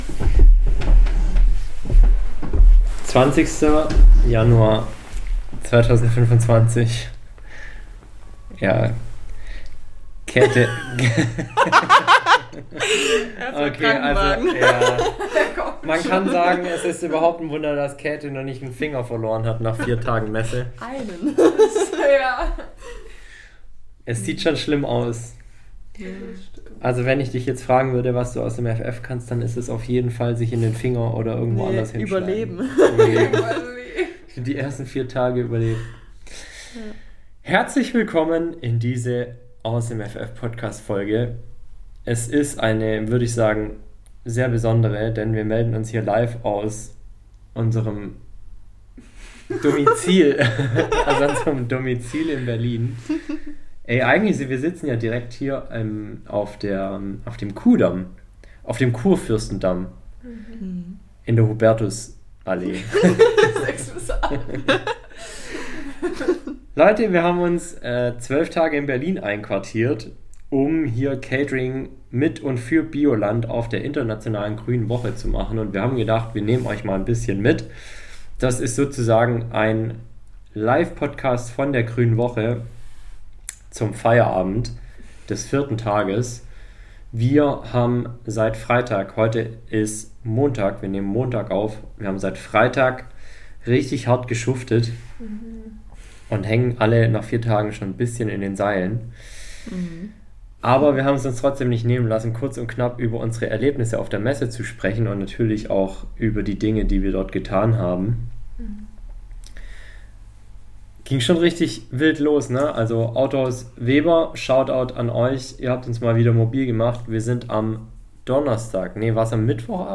Wir sind auf der Internationalen Grünen Woche in Berlin! Sozusagen fast-live berichten wir Dir von unseren ersten vier Tagen auf einer der größten Messen für Ernährung und Landwirtschaft weltweit und schildern unsere Eindrücke aus der Hauptstadt.